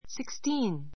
sixteen 小 A1 sikstíːn スィ クス ティ ーン 名詞 複 sixteens sikstíːnz スィ クス ティ ーン ズ 16 ; 16分; 16歳 さい , 16人[個] Lesson Sixteen (=The Sixteenth Lesson) 関連語 Lesson Sixteen The Sixteenth Lesson 第16課 It is sixteen to four.